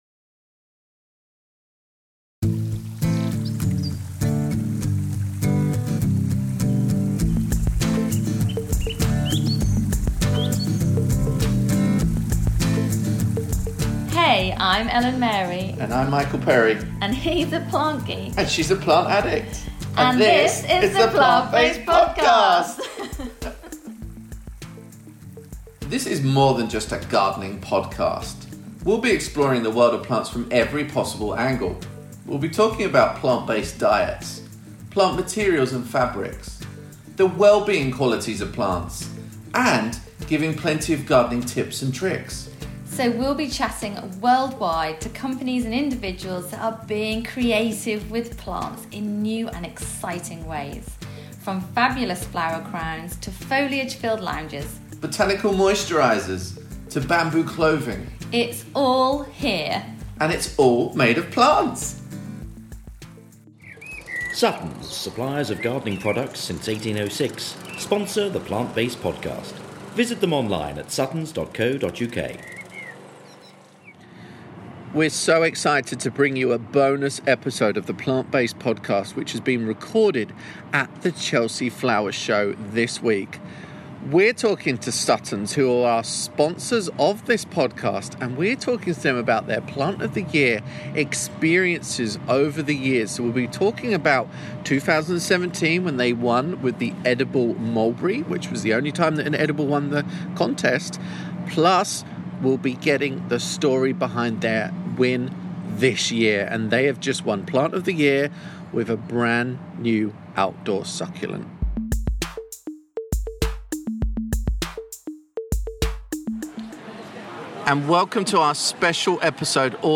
We visited the Chelsea Flower Show to get behind the scenes with some of the exhibitors and find out the rest of the news about the coveted Plant of the Year, Sedum Atlantis.